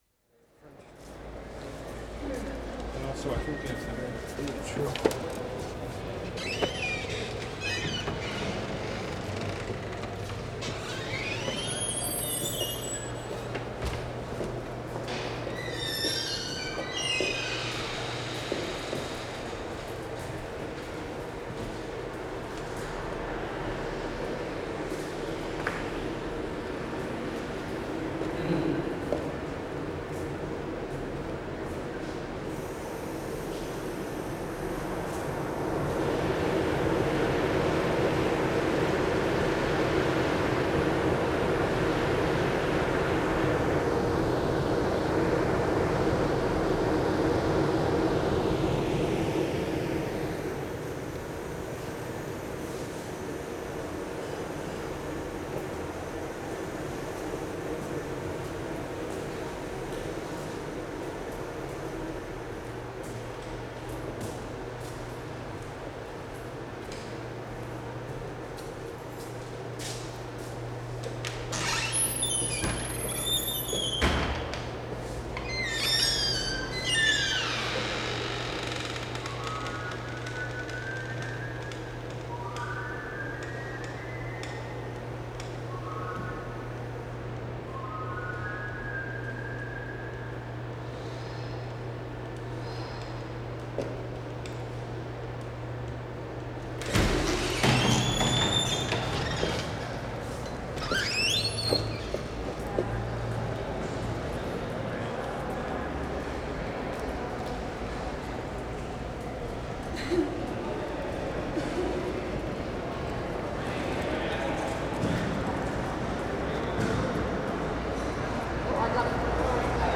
WORLD SOUNDSCAPE PROJECT TAPE LIBRARY
6. Walking in and out of the washroom, squeaky door, fan.
2'00" move outside, traffic ambience.